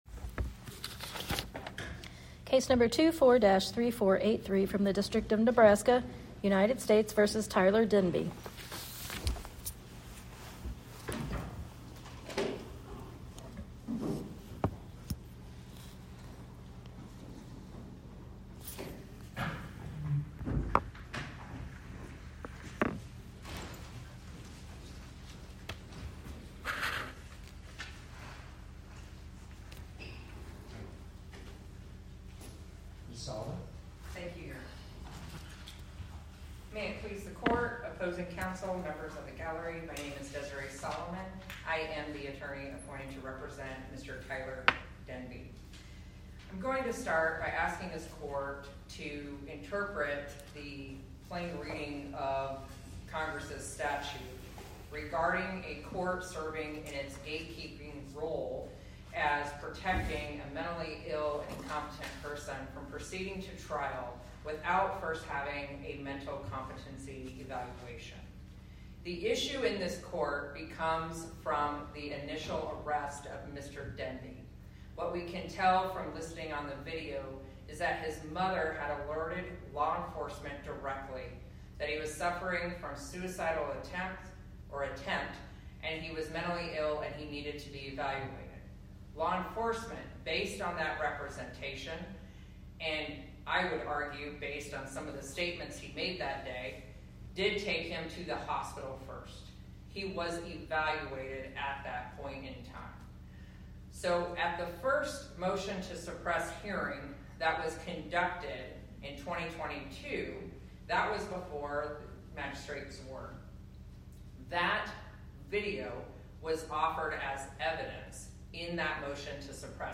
Oral argument argued before the Eighth Circuit U.S. Court of Appeals on or about 11/18/2025